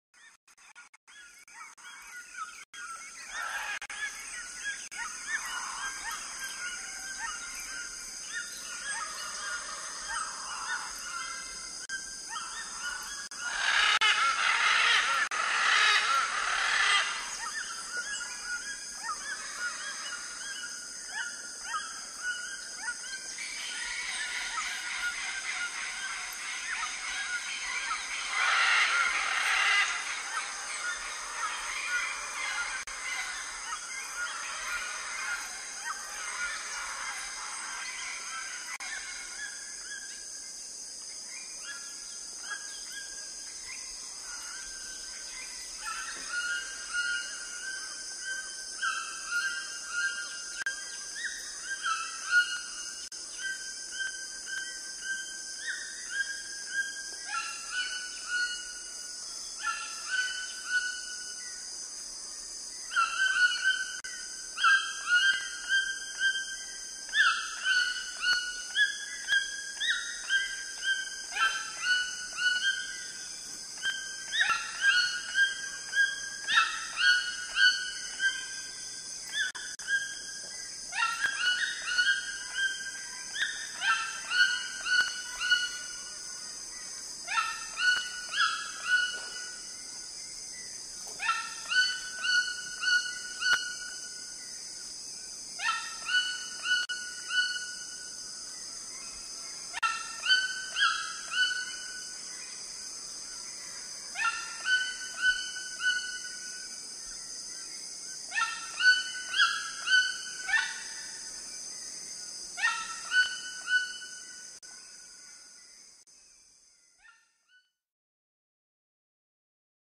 Sonidos-reales-del-bosque-inundado-del-amazona.mp3